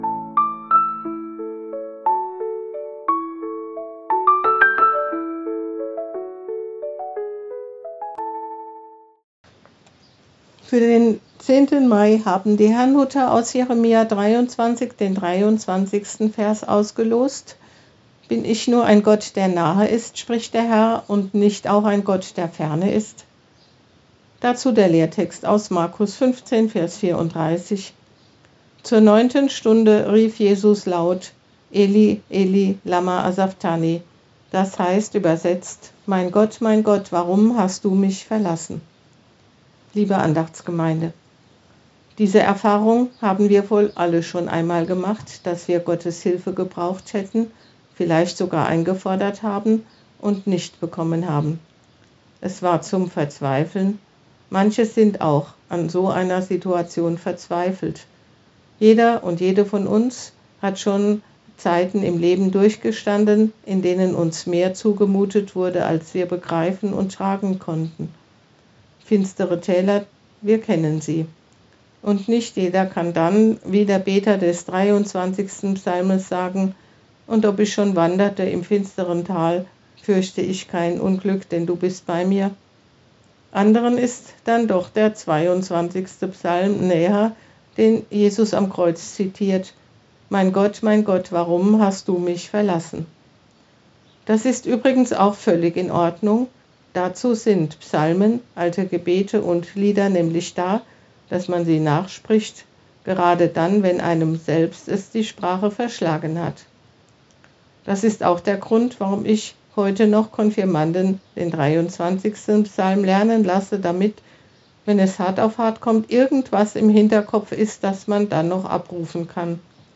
Losungsandacht für Samstag, 10.05.2025